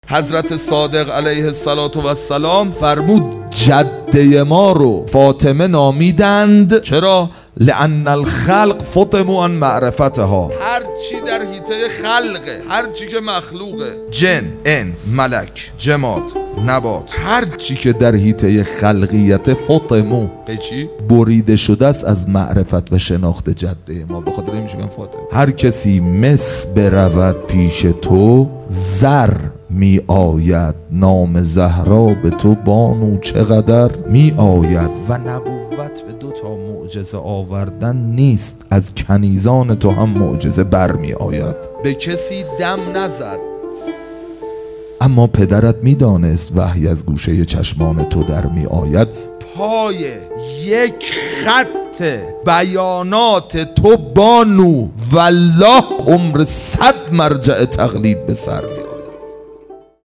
هیئت عشاق الرضا (ع) تهران | محرم 1399